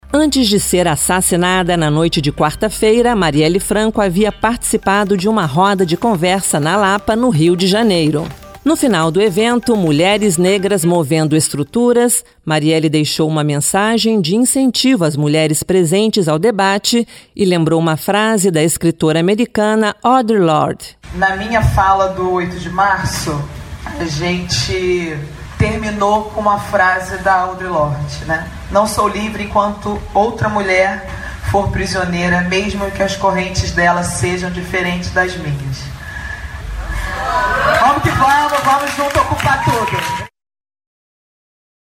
Mulher, negra e feminista, a vereadora do PSOL do Rio de Janeiro Marielle Franco, assassinada nesta quarta-feira (14), participou de seu último ato público na Lapa, um dos bairros mais tradicionais do Rio de Janeiro, horas antes do assassinato. No evento, uma roda de conversa, Marielle falou sobre os vários desafios das mulheres atualmente. A Rádio Senado traz o final da fala da vereadora, trecho que mostra a essência do pensamento de Marielle: a luta pelo direito de todas as mulheres.